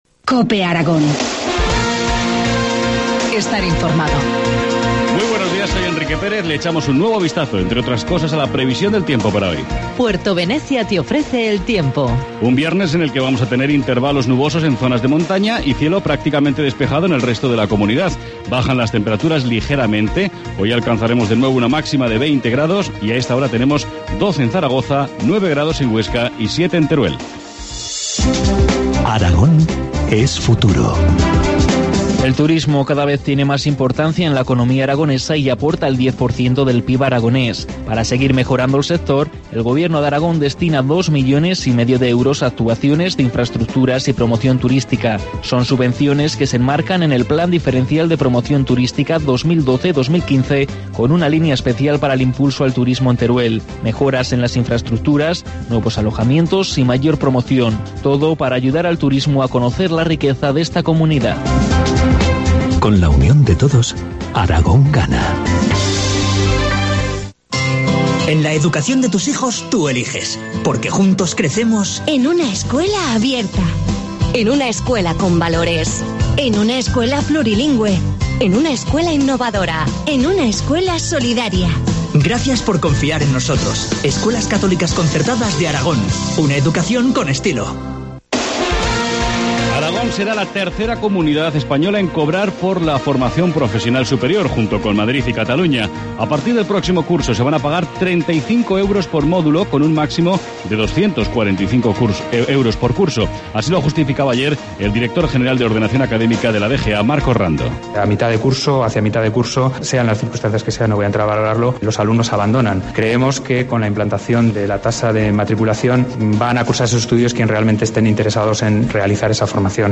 Informativo matinal, viernes 12 de abril, 8.25 horas